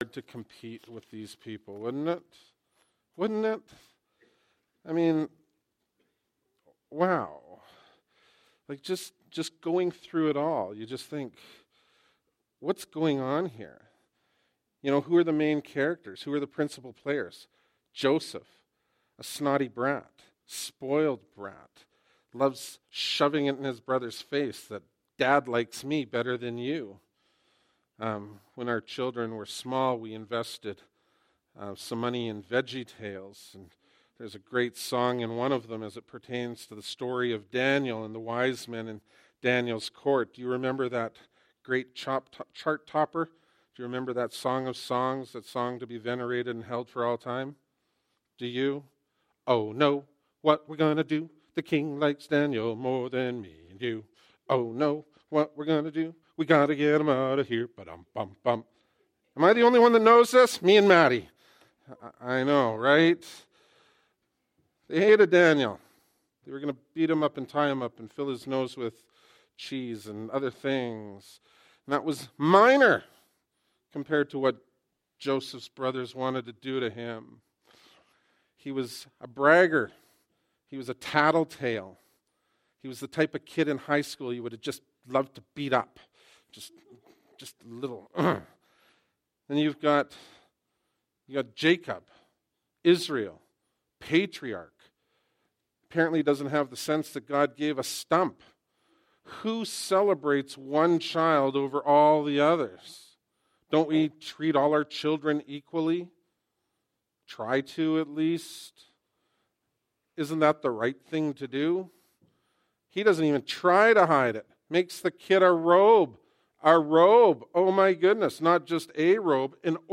Bible Text: Genesis 37 | Preacher